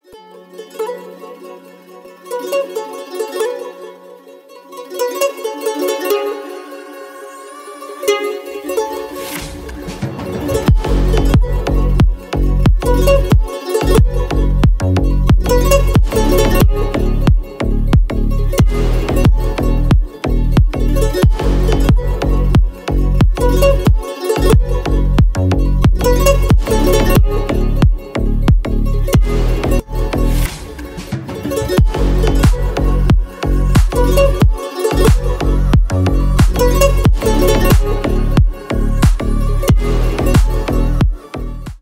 Ремикс
громкие # грустные # без слов